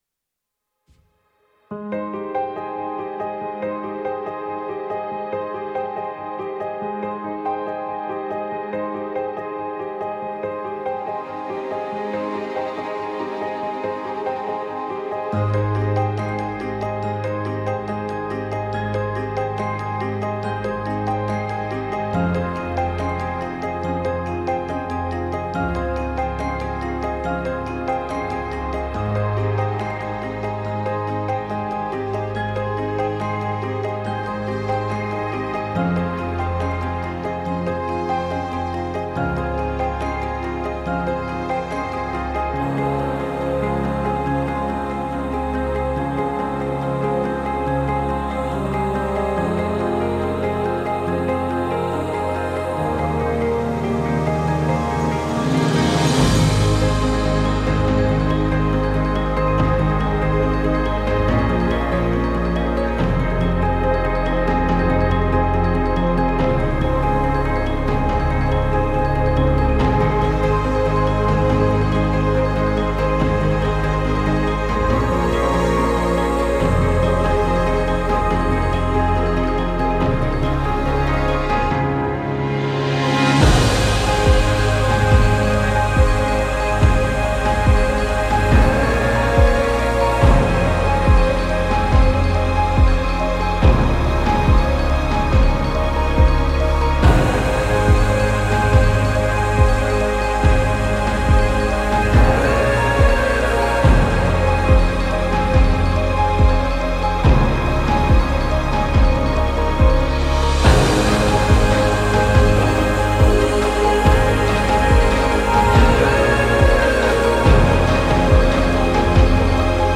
instrumental